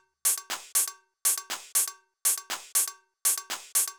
Drumloop 120bpm 01-B.wav